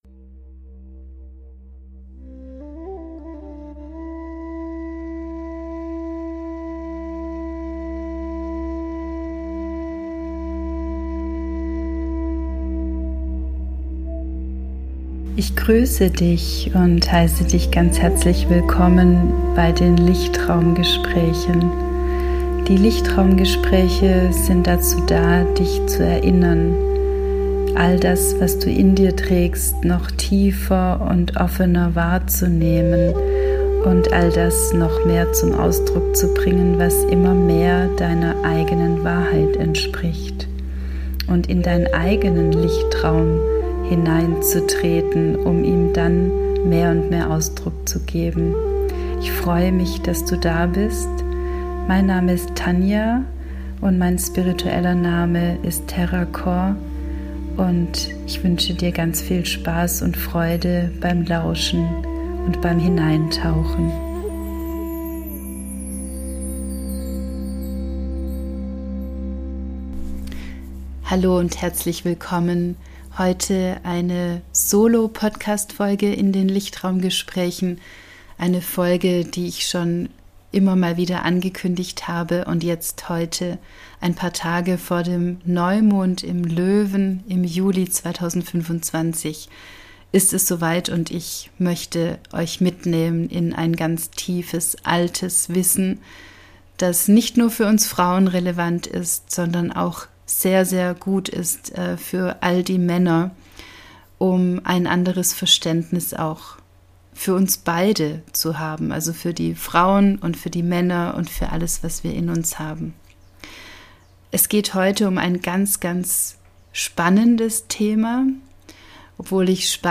In dieser Solo-Podcastfolge gebe ich Dir wesentliche Informationen weiter zu den 11 Mondpunkten von uns Frauen. Jede Frau hat diese 11 Mondpunkte im Laufe ihres gesamten Lebens aktiviert und doch wissen wir darüber fast gar nichts.